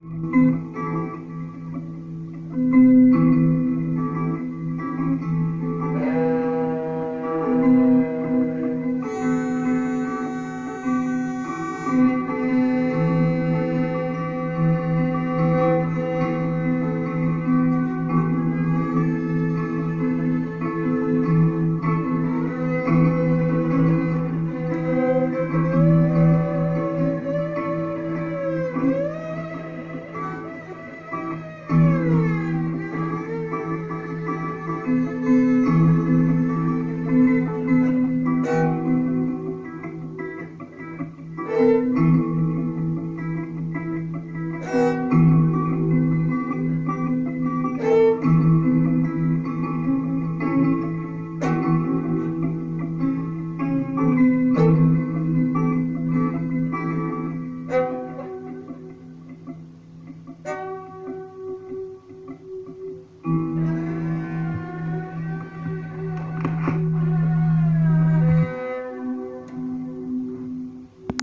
earthy, organic and exploratory